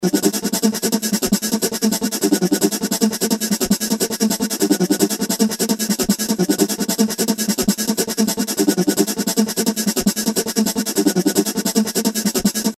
Shaker Synth.wav